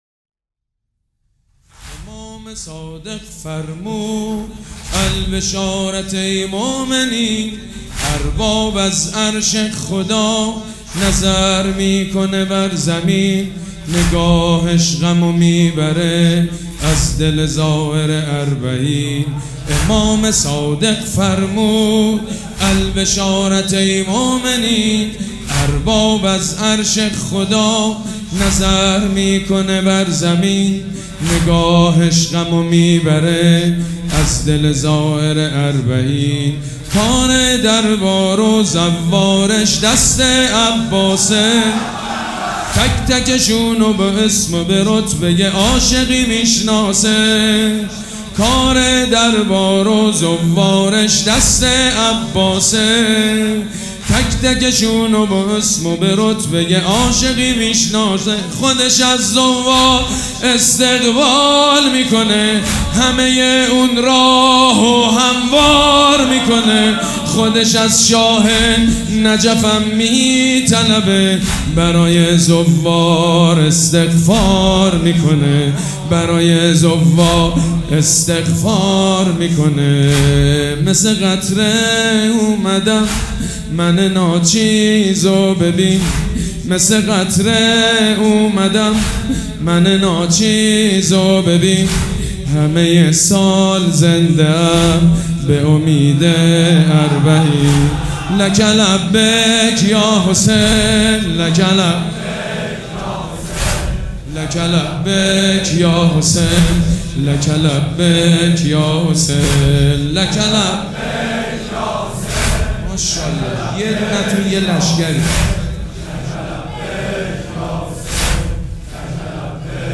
مداح
حاج سید مجید بنی فاطمه
مراسم عزاداری شب چهارم